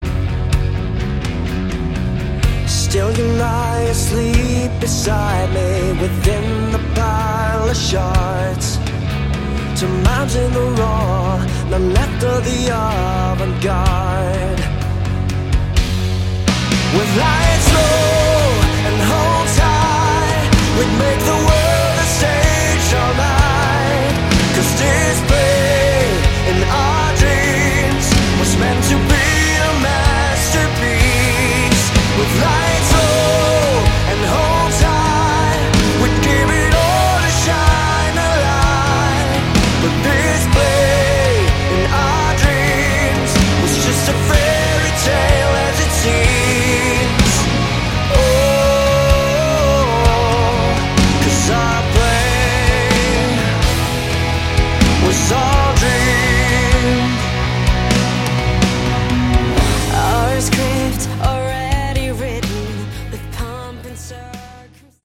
Category: Hard Rock
vocals
guitar
bass
drums